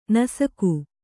♪ nasaku